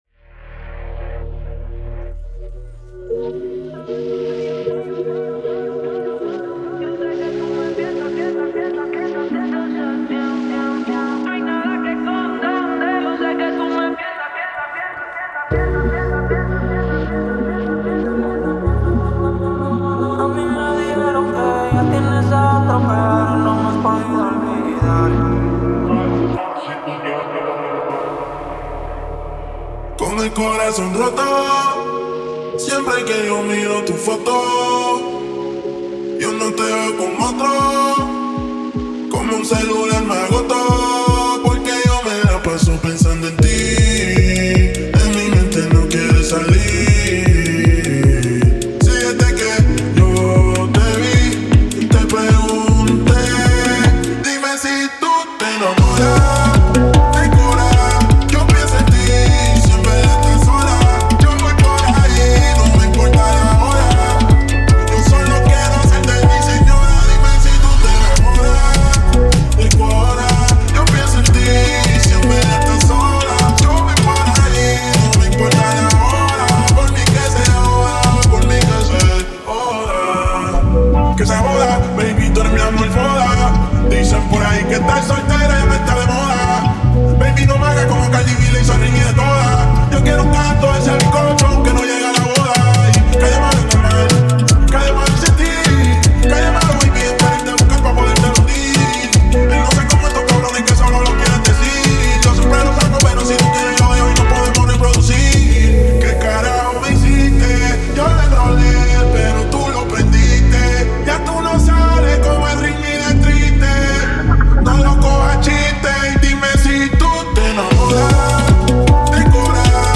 155 bpm